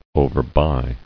[o·ver·buy]